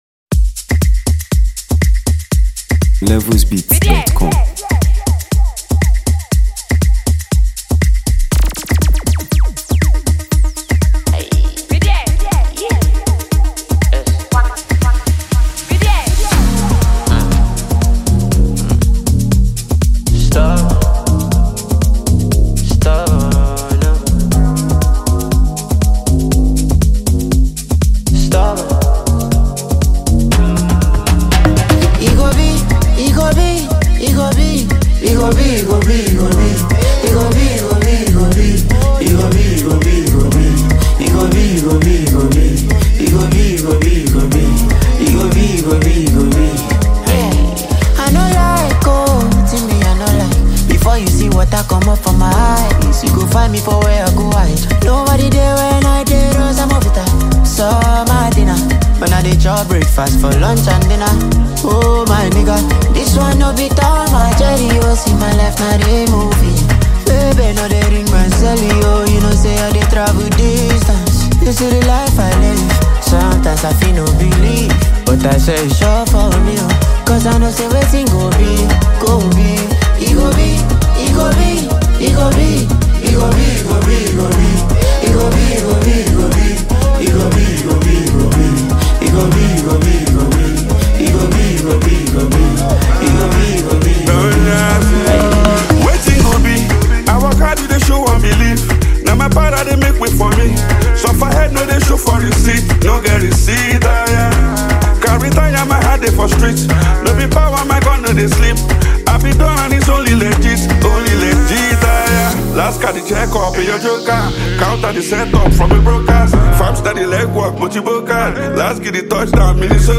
the legendary Nigerian music producer